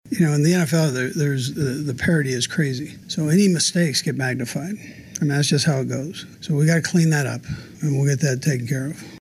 Chiefs head coach Andy Reid says they will learn from the mistakes in this one.